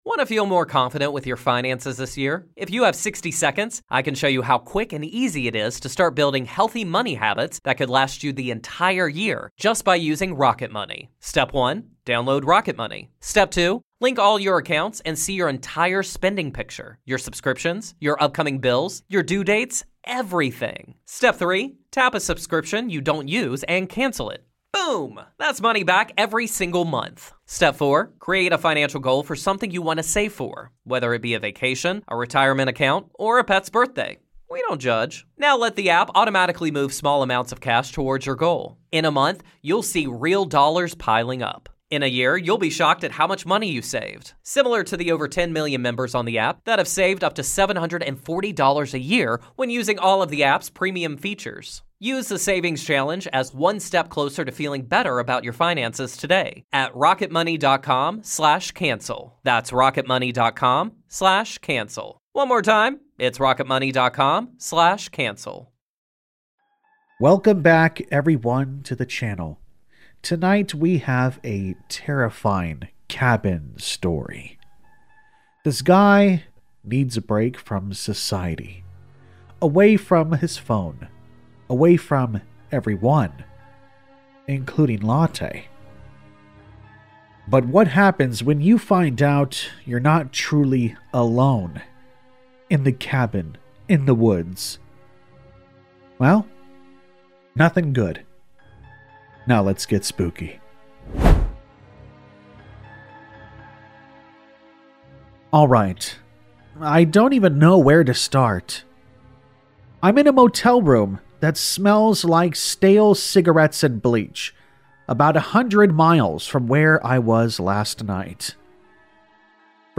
Feb 05, 11:00 PM Headliner Embed Embed code See more options Share Facebook X Subscribe In tonight’s terrifying creepypasta, I’m Alone in a Cabin—And Something Knocks Every Night, we dive into a chilling tale of isolation, paranoia, and a growing presence that refuses to stay outside. This horror story delivers the perfect blend of atmospheric dread and suspense as nightly knocking turns into something far more sinister.
All Stories are read with full permission from the authors: